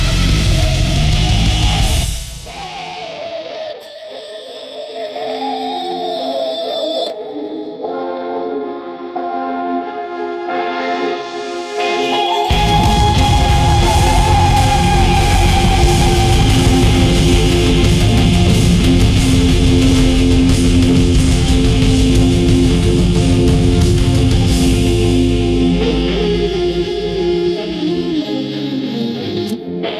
audio-to-audio music-generation